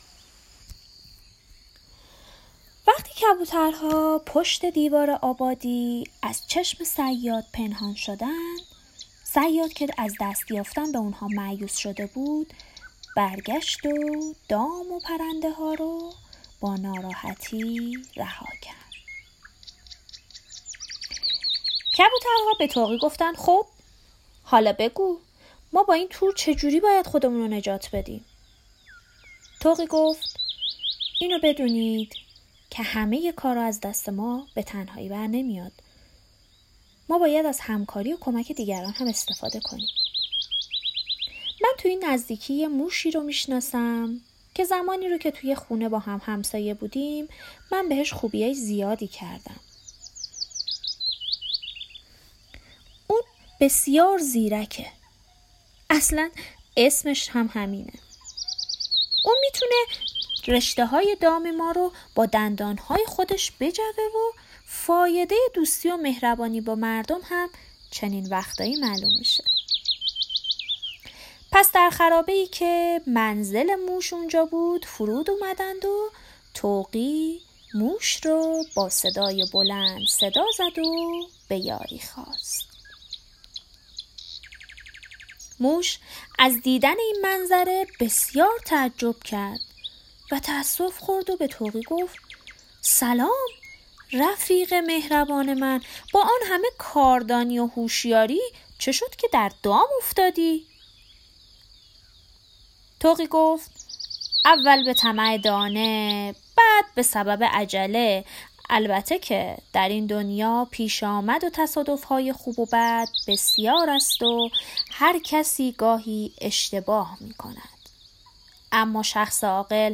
قصه شب...